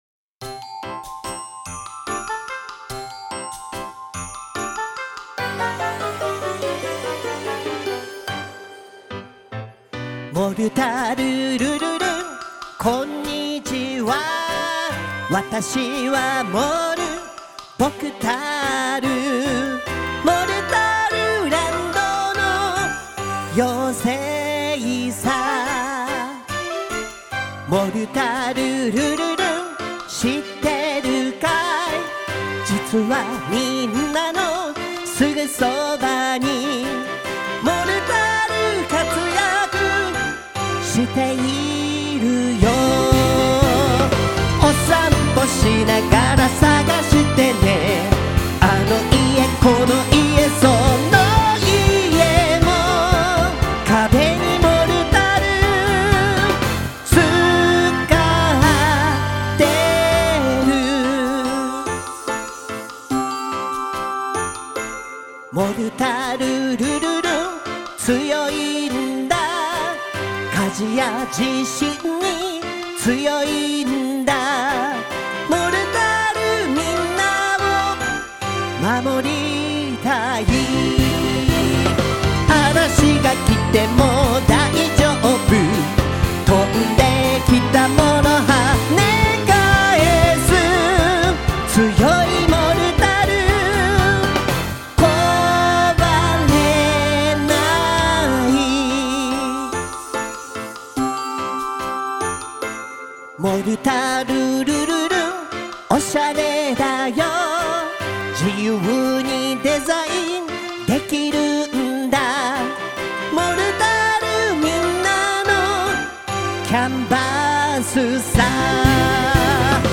曲名の通り、気持ちがルルルンと乗ってくる曲です。